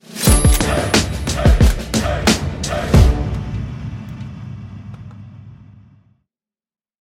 Звуки победы в игре